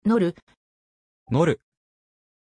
Pronunciation of Noor
pronunciation-noor-ja.mp3